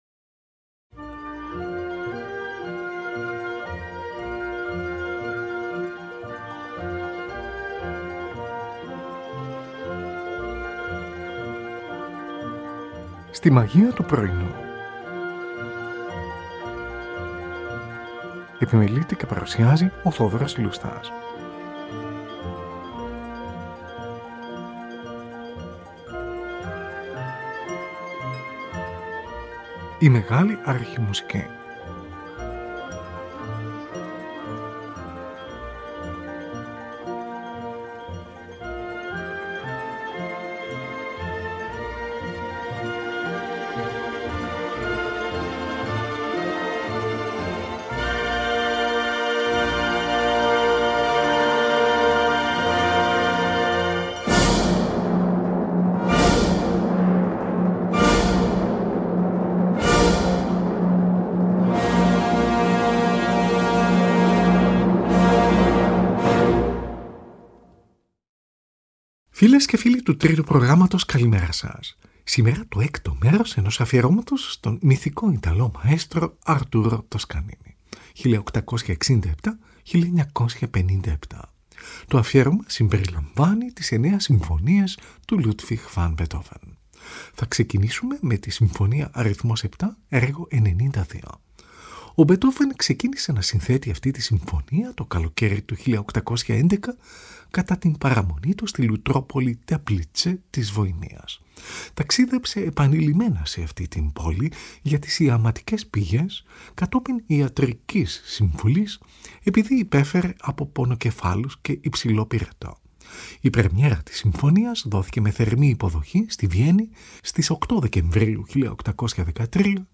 Tη Συμφωνική του NBC διευθύνει ο Arturo Toscanini . Zωντανή ραδιοφωνική μετάδοση στις 4 Νοεμβρίου 1939 .
Τη Συμφωνική του NBC διευθύνει ο Arturo Toscanini , από δοκιμή στις 23 Νοεμβρίου 1946 . Ένα ενδιαφέρον ντοκουμέντο , το οποίο -παρά τους έντονους παρασιτικούς ήχους- φωτίζει τις ερμηνευτικές αντιλήψεις του μυθικού μαέστρου, τον τρόπο συνεργασίας του με τους μουσικούς , με επιμονή σε κάθε λεπτομέρεια, καθώς και τη μοναδική ιδιοσυγκρασία του .